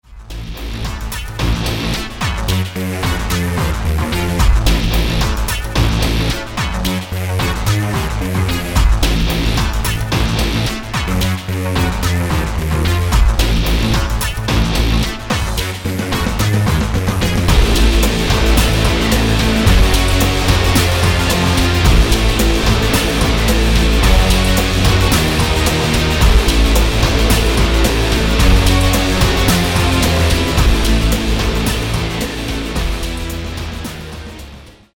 Here’s a quick preview of what I’m working on. It’s a quick instrumental, but hell, it’s better than nothing!